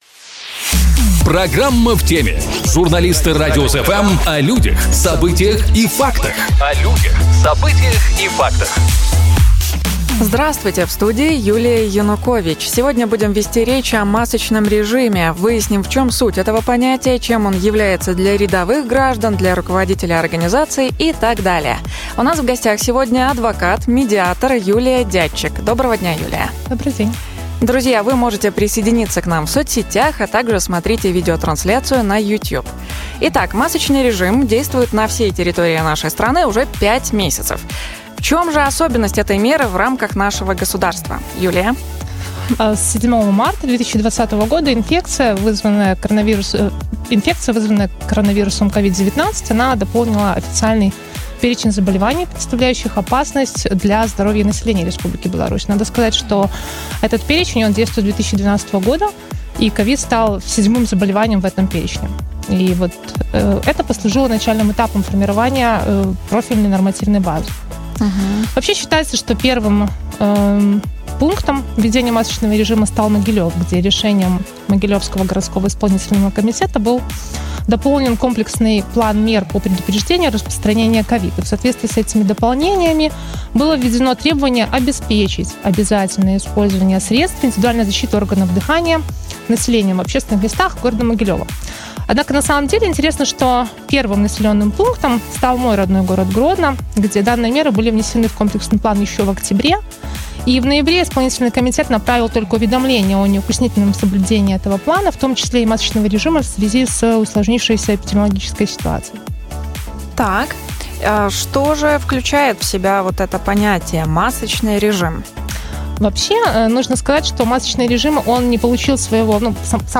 У нас в гостях - адвокат, медиатор